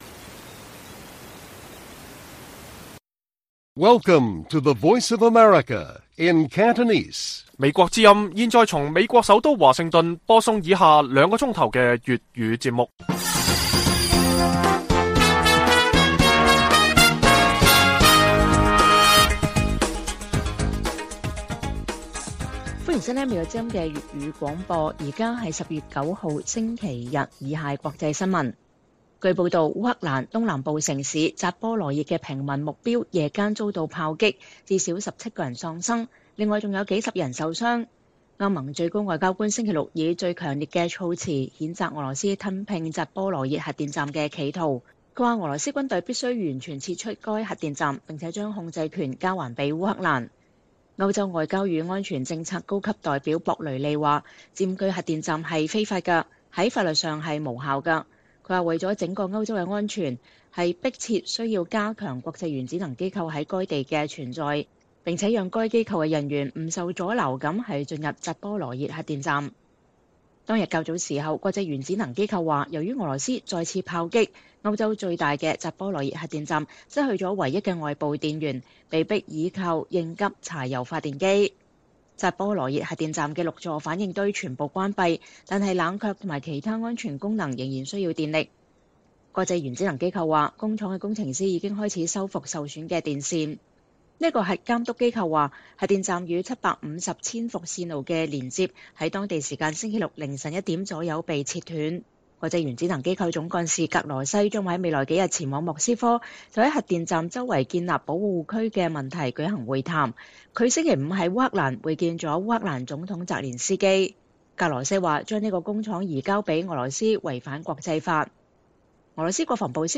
粵語新聞 晚上9-10點：烏克蘭扎波羅熱遭到砲擊 17死數十傷